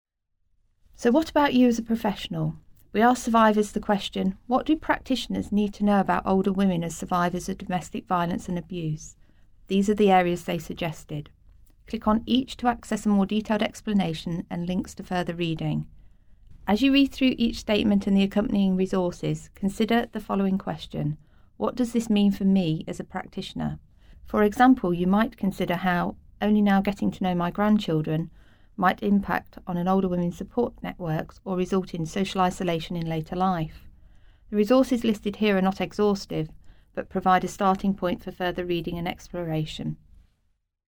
Narration audio (MP4) Narration audio (OGG) Contents Home Introduction My Words - Survivor poems Images of Survivorship Your Word/Tag Cloud What do practitioners need to know about us?